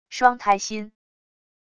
双胎心wav音频